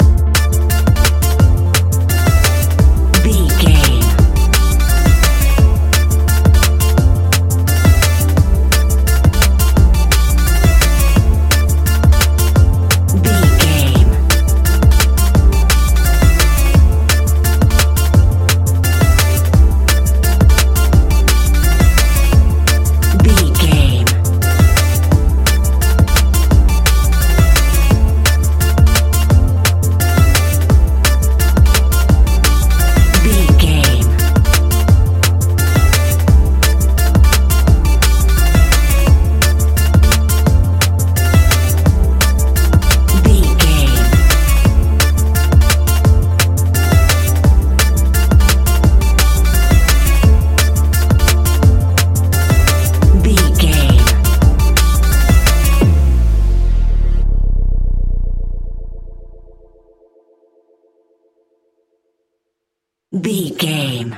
Ionian/Major
electronic
techno
trance
synths
instrumentals